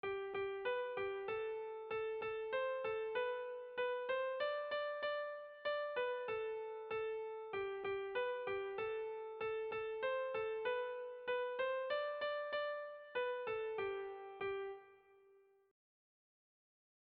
Sentimenduzkoa
Doinu ederra.
Seiko handia (hg) / Hiru puntuko handia (ip)